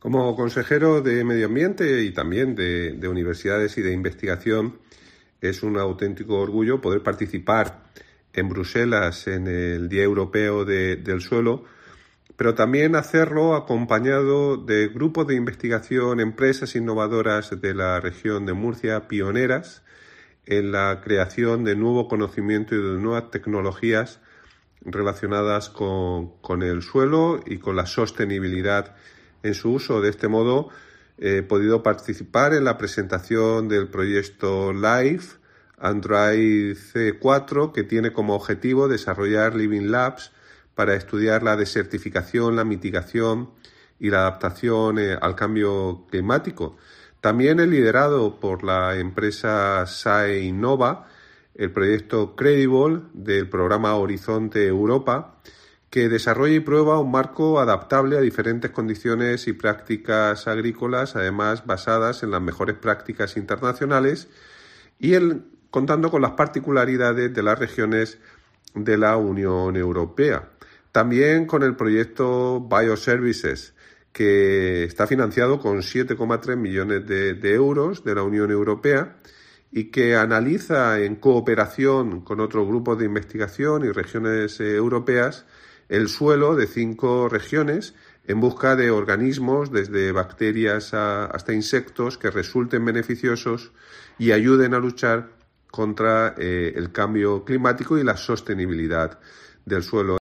Juan María Vázquez, consejero de Medio Ambiente, Universidades, Investigación y Mar Menor